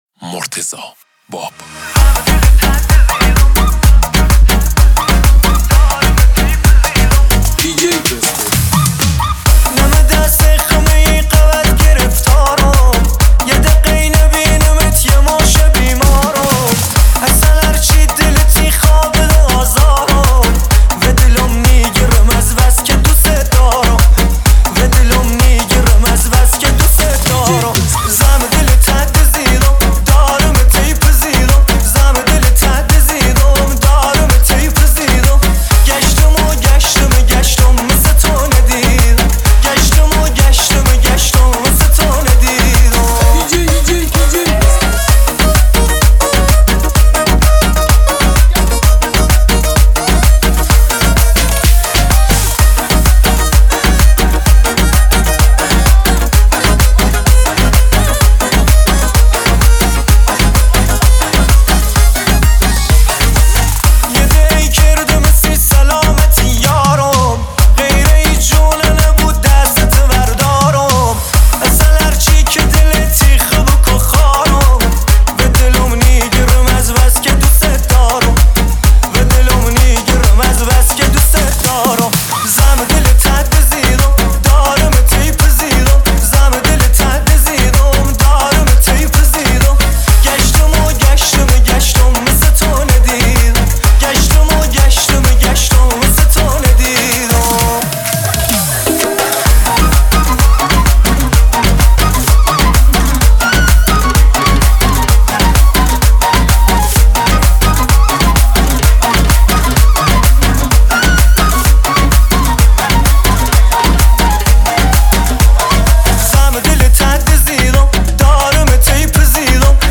تند بیس دار